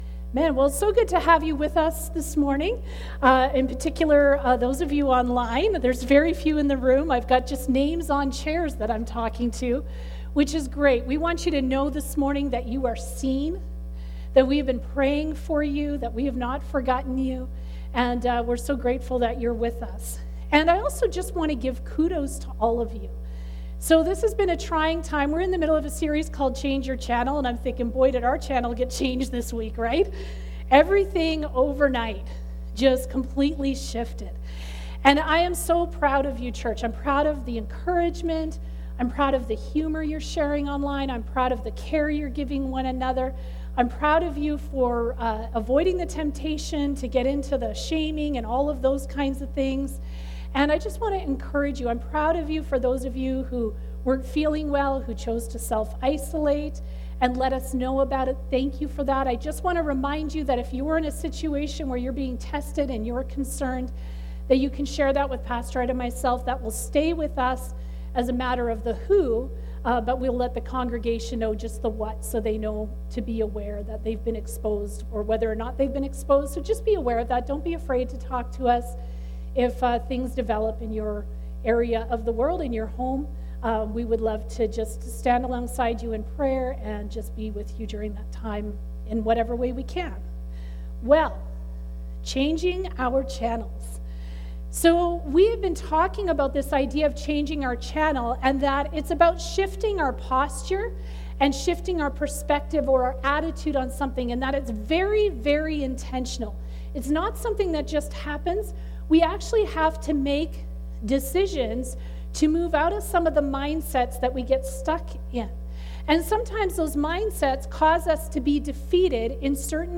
Joshua 9:3-16 Service Type: Sunday Service Sermon at 38 minutes.